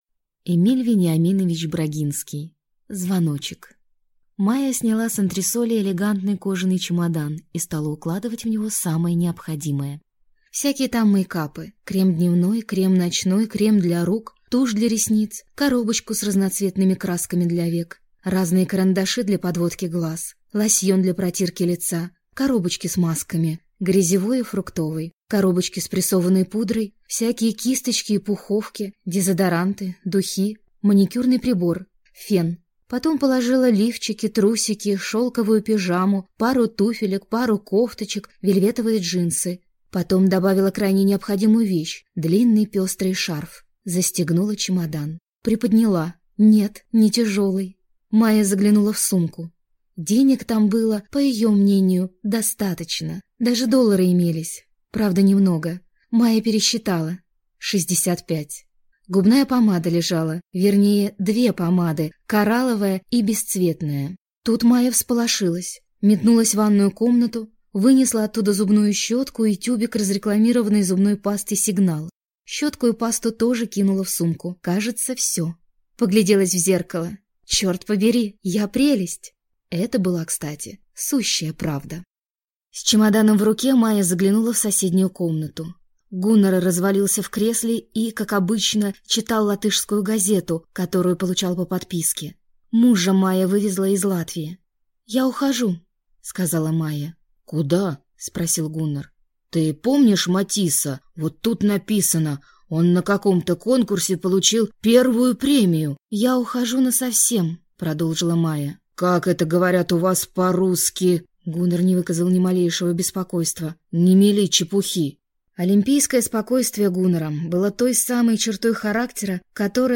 Aудиокнига Звоночек